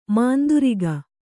♪ mānduriga